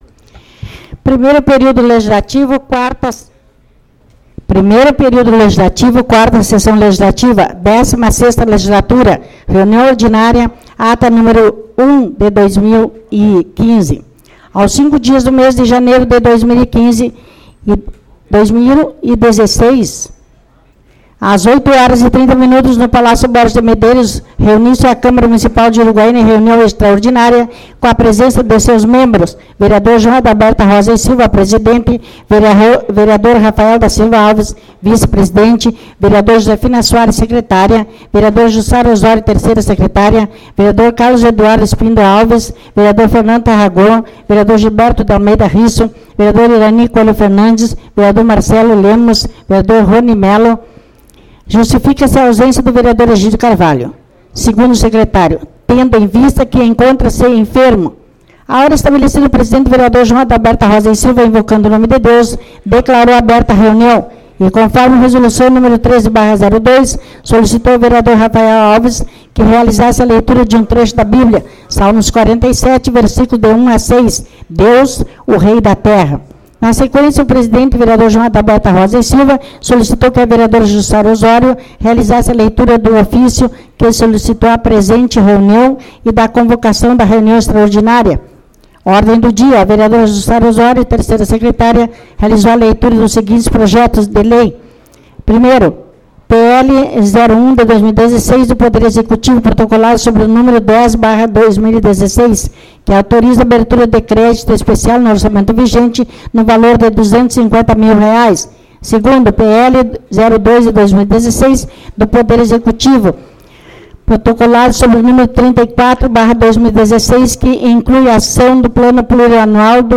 05/02 - Reunião Extraordinária